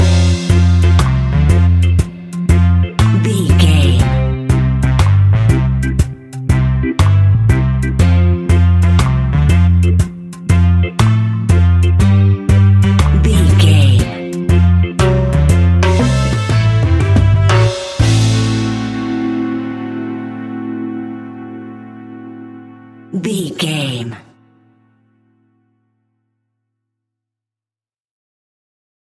Classic reggae music with that skank bounce reggae feeling.
Aeolian/Minor
laid back
off beat
skank guitar
hammond organ
percussion
horns